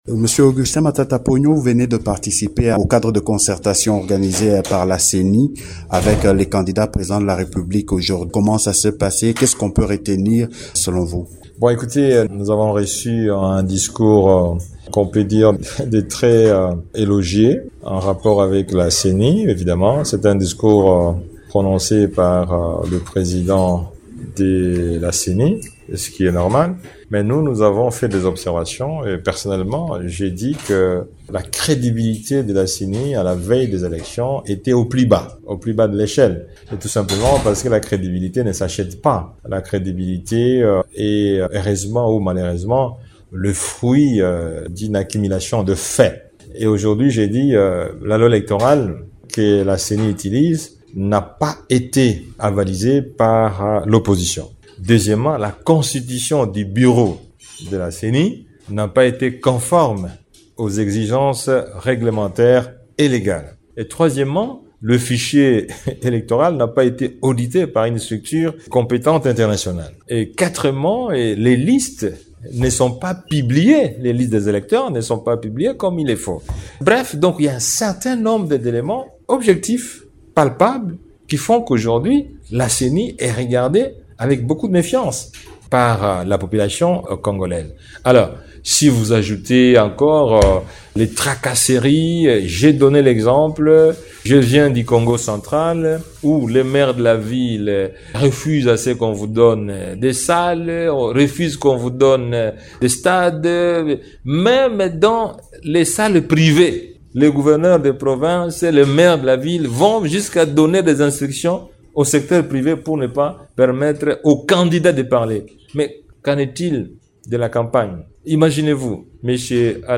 Candidat à la présidentielle, Augustin Matata Ponyo, s’exprime sur le cadre de concertation organisé par la Commission électorale nationale indépendante (CENI) ainsi que sur la réunion que les délégués des opposants tiennent en Afrique du Sud. Invité de Radio Okapi ce mardi 14 novembre, il affirme que la CENI a confirmé l’organisation des élections le 20 décembre 2023.
Augustin Matata Ponyo s’entretient